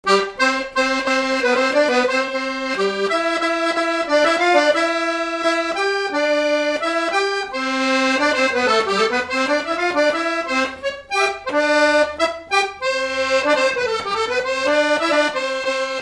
scottish à sept pas
danse : scottish (autres)
Pièce musicale inédite